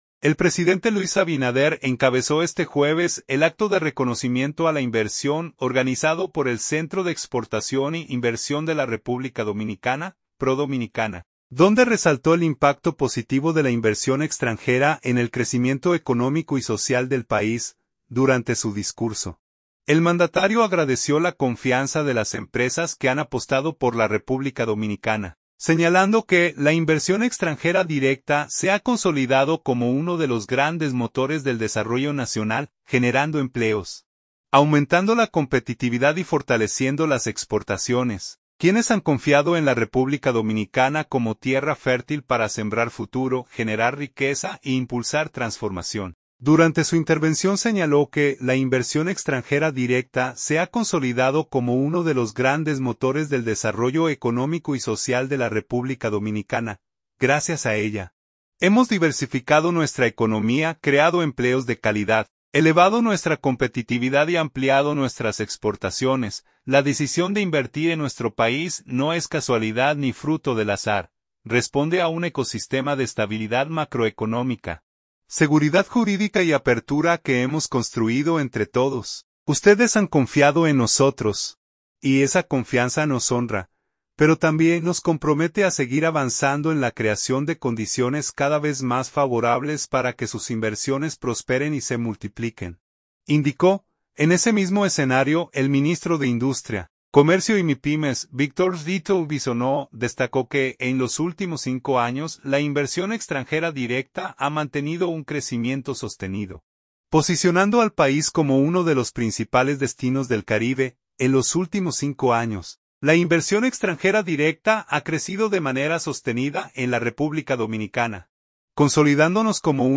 Durante su discurso, el mandatario agradeció la confianza de las empresas que han apostado por la República Dominicana, señalando que la inversión extranjera directa se ha consolidado como uno de los grandes motores del desarrollo nacional, generando empleos, aumentando la competitividad y fortaleciendo las exportaciones.